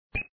bullet_hit.ogg